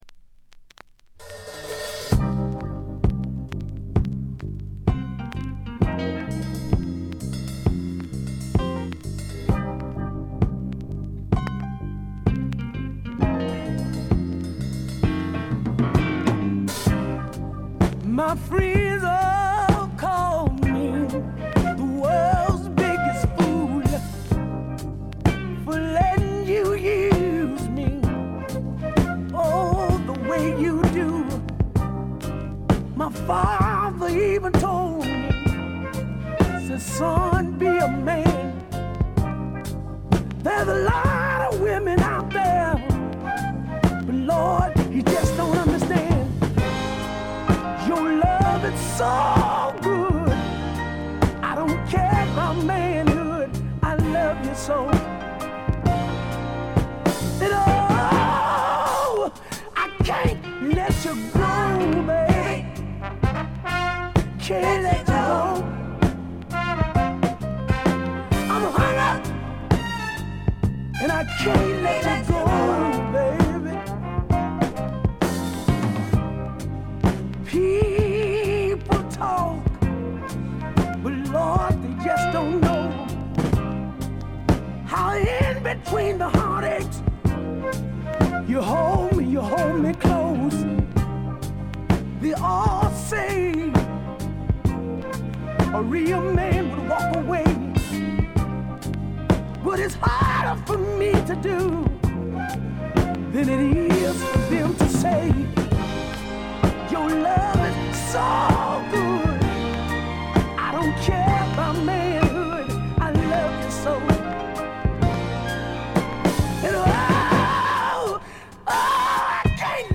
バックグラウンドノイズ、チリプチ多め大きめです（特にA面）。A3は途中で軽い周回気味のノイズも出ます。
栄光の60年代スタックス・ソウルの正統なる継承者とも言えるディープなヴォーカルと適度なメロウさがたまらないです。
試聴曲は現品からの取り込み音源です。